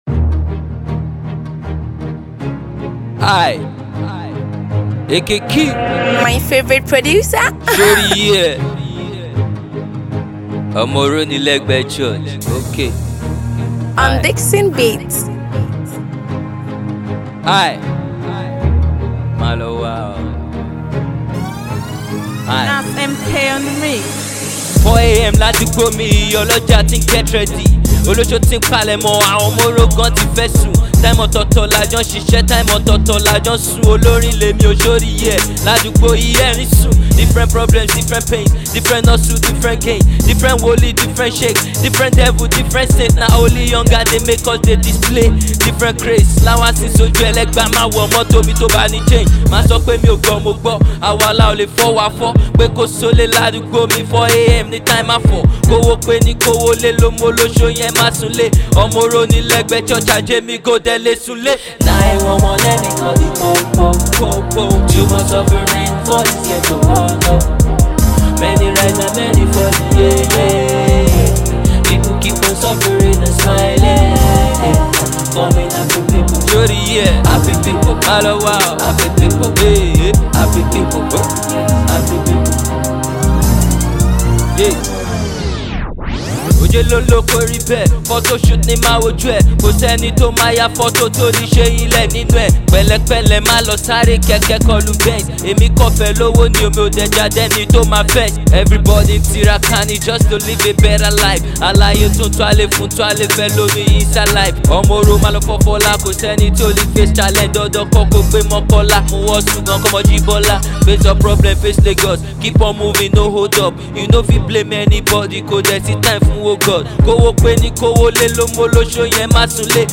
Indigenous (Ibile) Rapper